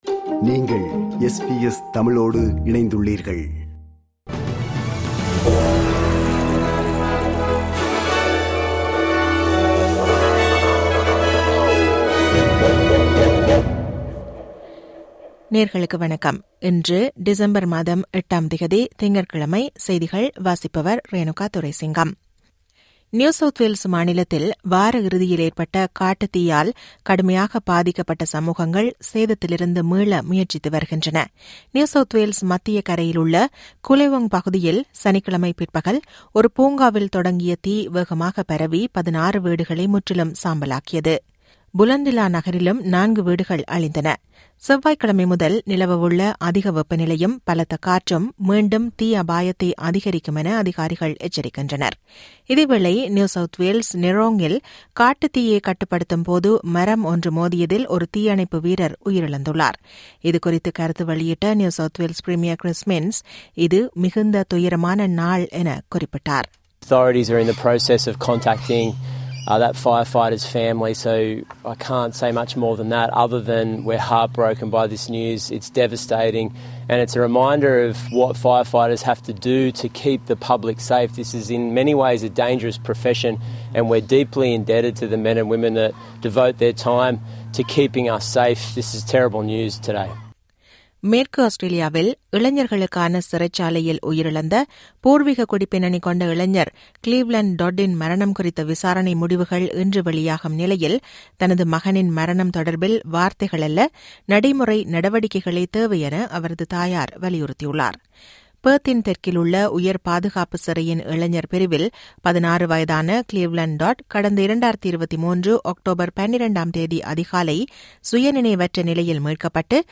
இன்றைய செய்திகள்: 08 டிசம்பர் 2025 - திங்கட்கிழமை